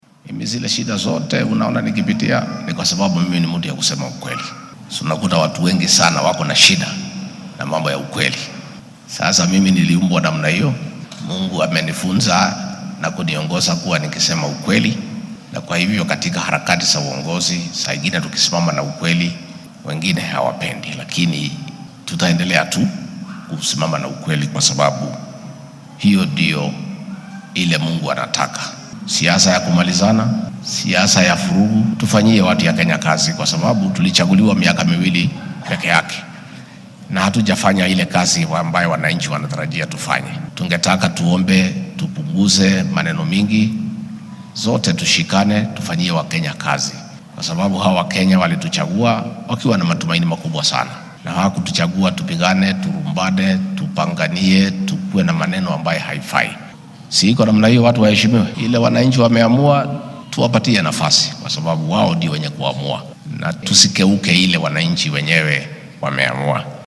Xilli uu munaasabad kaniiseed uga qayb galay ismaamulka Meru ayuu Gachagua xusay in mooshinka xil ka qaadista ee isaga ka dhanka ah ee la hadal hayo uu yahay mid la doonaya in lagu weeciyo rabitaanka shacabka.